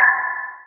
Perc 10.wav